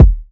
Kick (5).wav